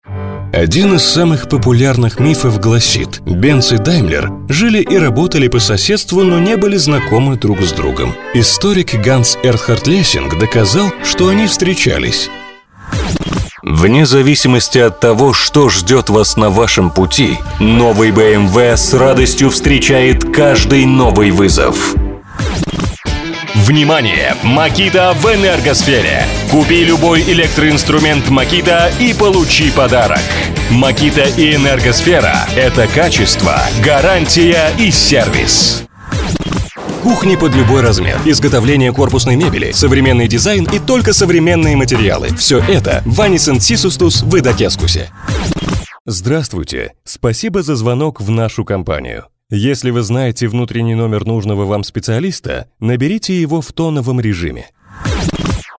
23 года, г.Саратов, актёр озвучания.
Дикторская кабина микрофон: RODE NT1-a интерфейс: steinberg UR12